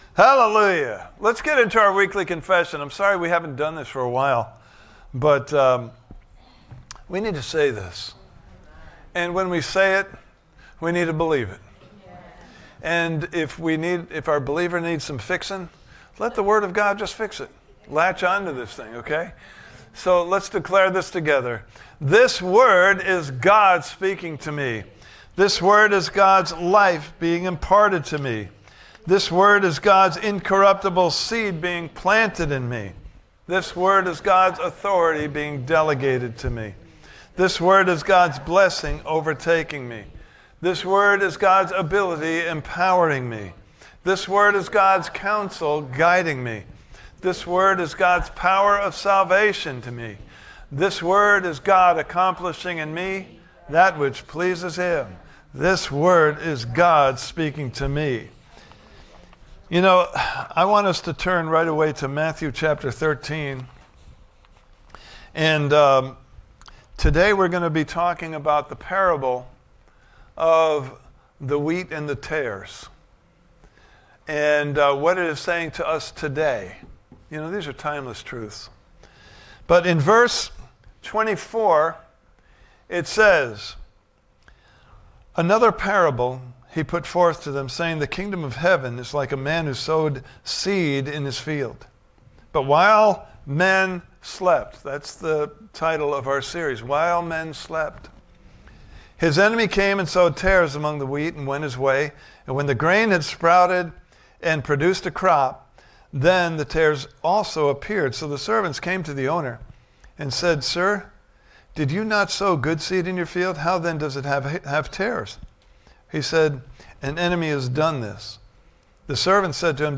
While Men Slept Service Type: Sunday Morning Service « Part 1